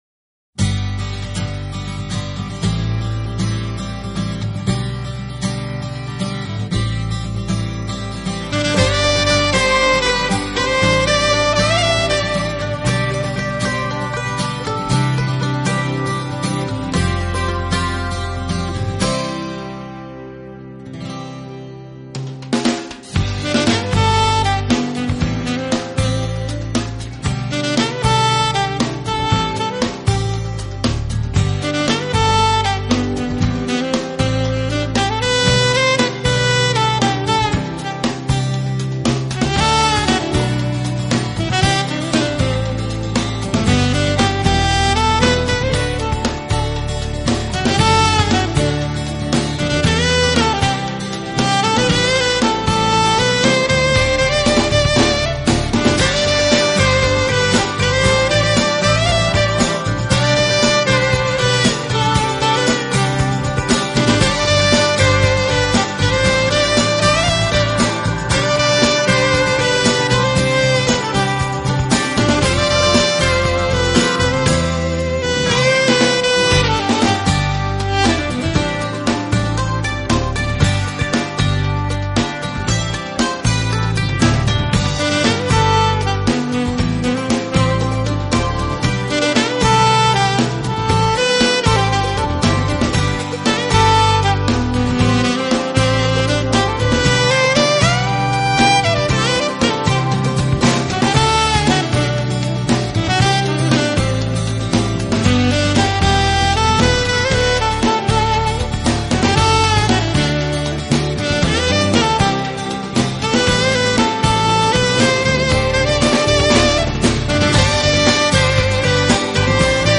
【爵士萨克斯】
旋律流暢輕快。